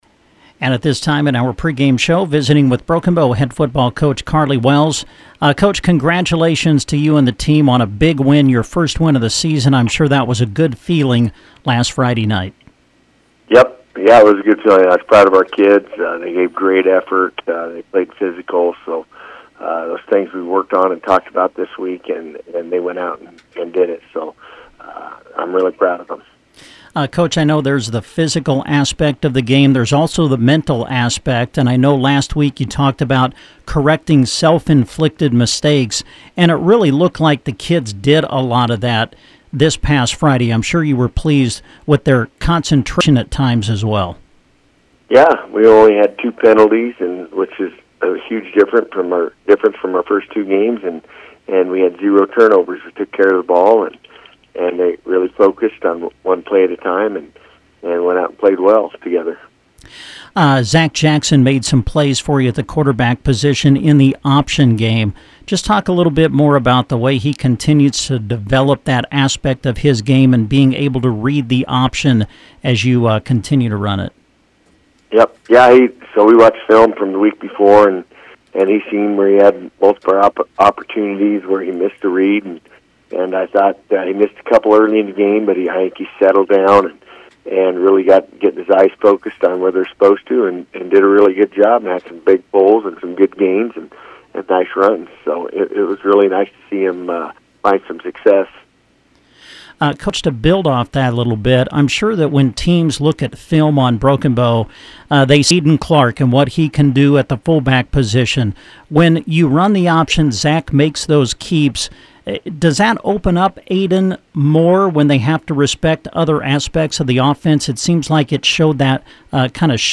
The interview is posted below.